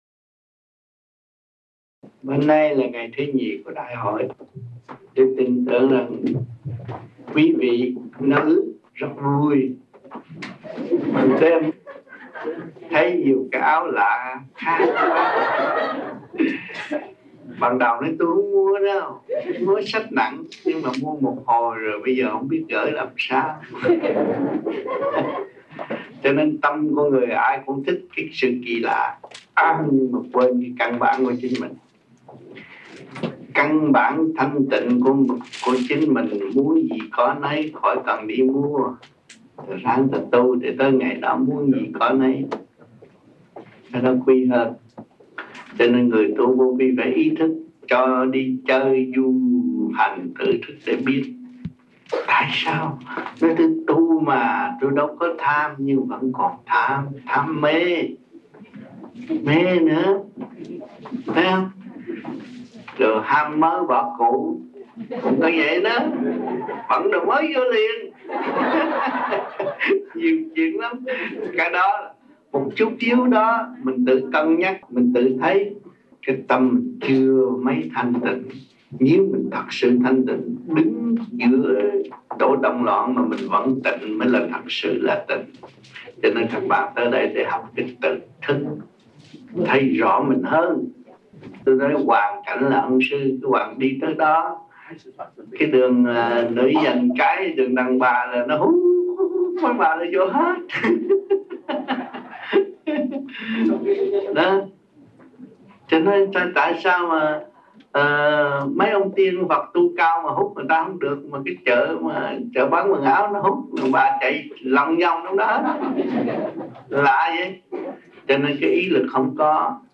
Băng Giảng Và Vấn Đạo Tại Những Đại Hội Vô Vi Quốc Tế